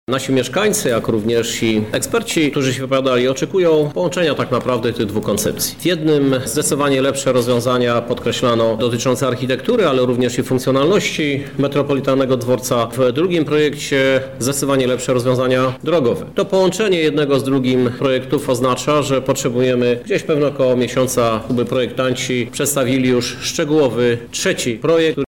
Projekt budynku z jednej i plany zmiany przebiegu ulic z drugiej. Szczegóły tłumaczy Prezydent Lublina Krzysztof Żuk: